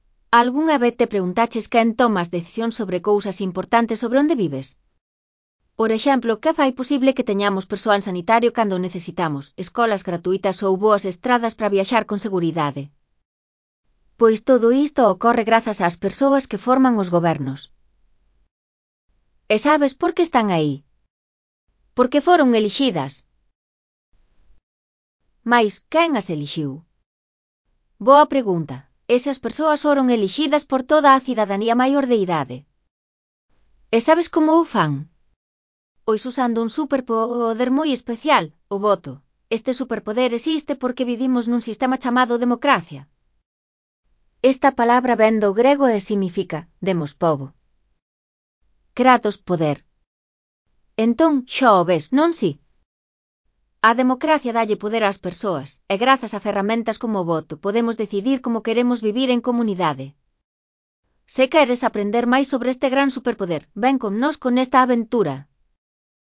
Elaboración propia (proxecto cREAgal) con apoio de IA, voz sintética xerada co modelo Celtia. Superheroes na democracia (CC BY-NC-SA 4.0)